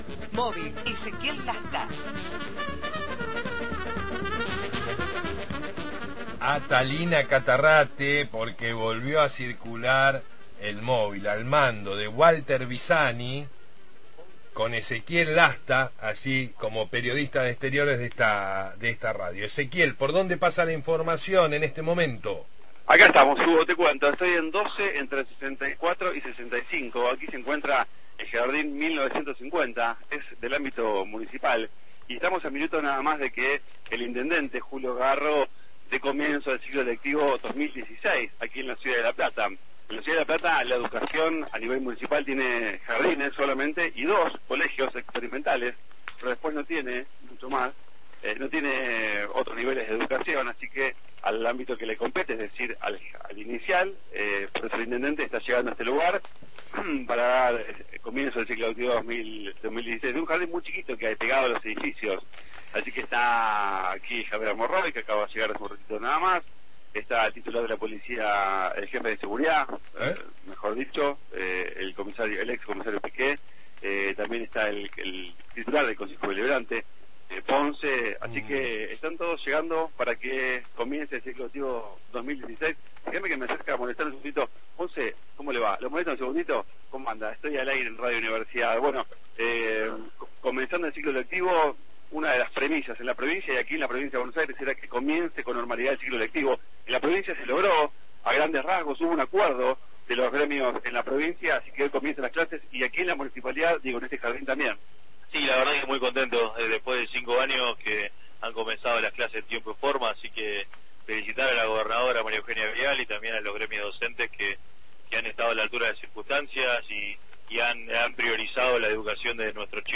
MÓVIL/ Julio Garro en el inicio del ciclo lectivo 2016
El intendente de la ciudad, Julio Garro, participó del acto de inicio del ciclo lectivo 2016, en el Jardín Municipal n° 7, ubicado en 12 entre 64 y 65.